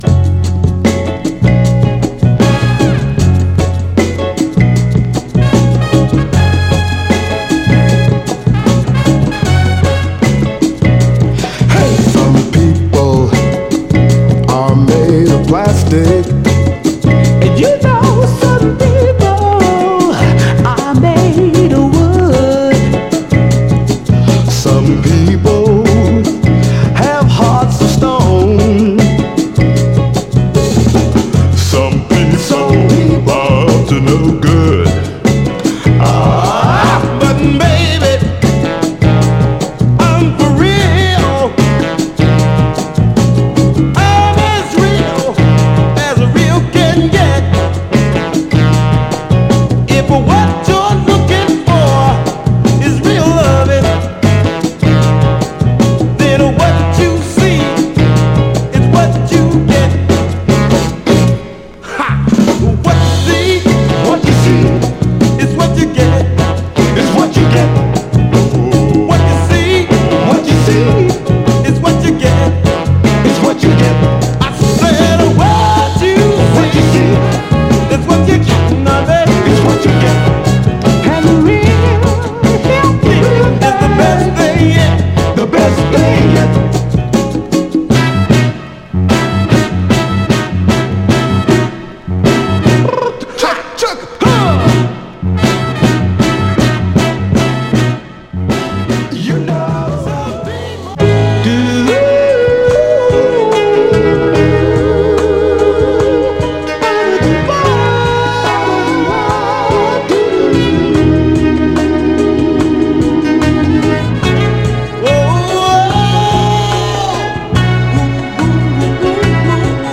※試聴音源は実際にお送りする商品から録音したものです※